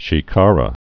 (shē-kärə)